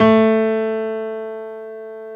55p-pno17-A2.wav